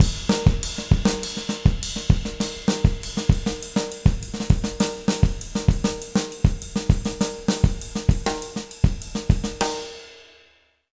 100SONGO05-R.wav